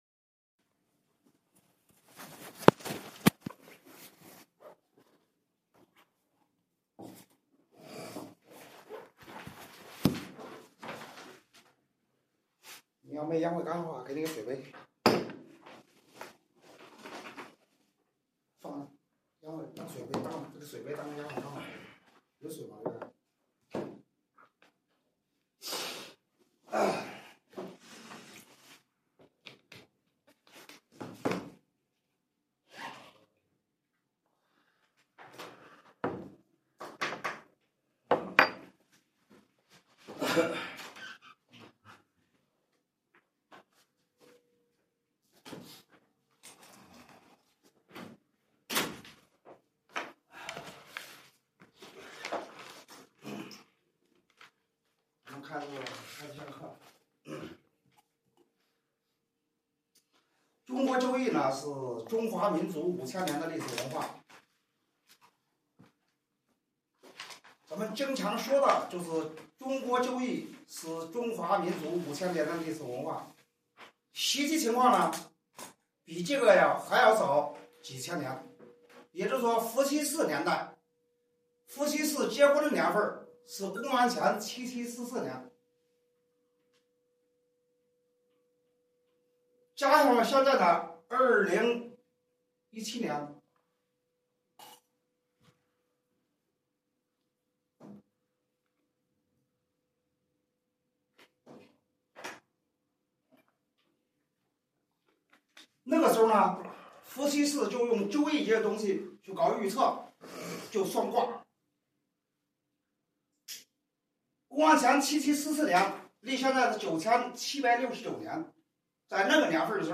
民间农村风水 面授班》录音+课堂讲义笔记百度网盘分享